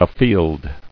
[a·field]